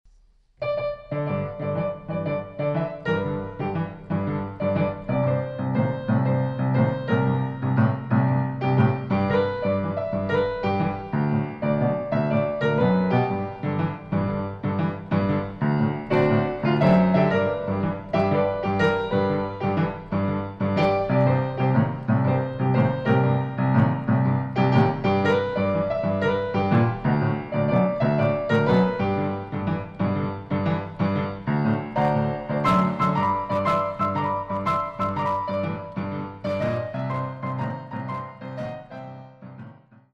Style: Jump Blues Piano